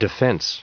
Prononciation du mot defense en anglais (fichier audio)
Prononciation du mot : defense